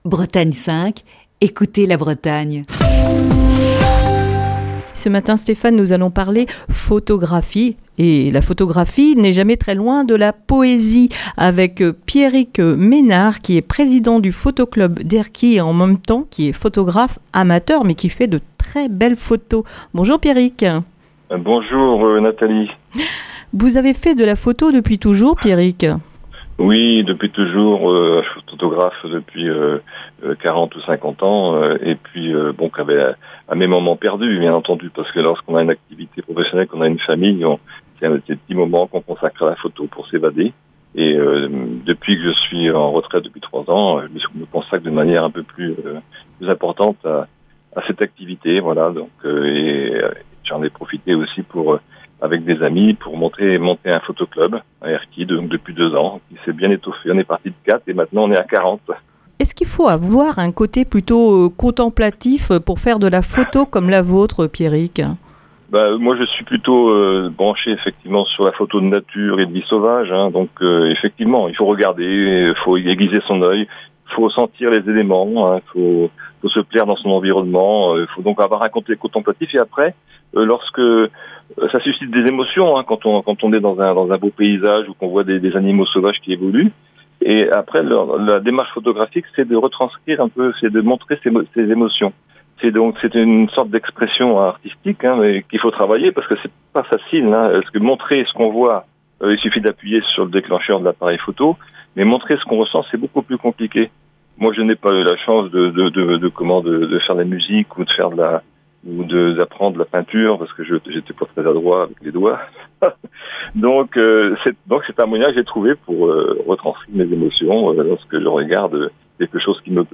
Émission du 16 décembre 2020. Ce jeudi on parle photo dans Le Coup de fil du matin.